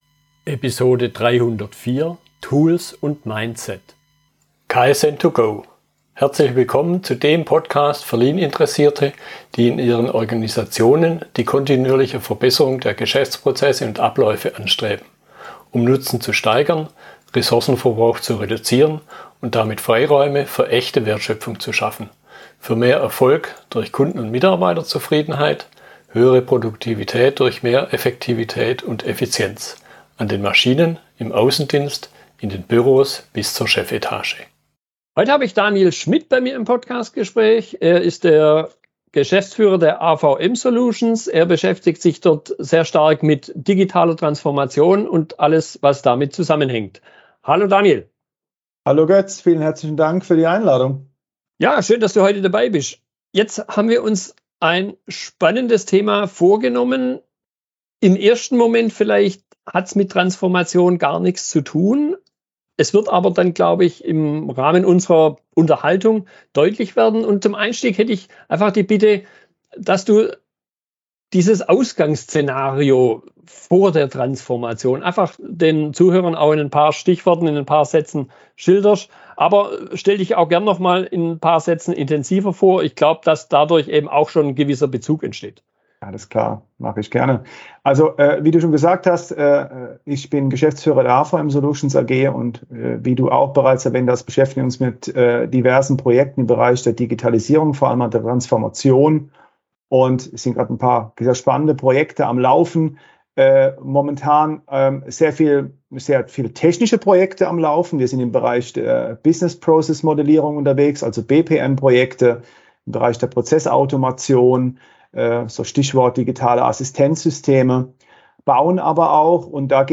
Fragestellungen in der Unterhaltung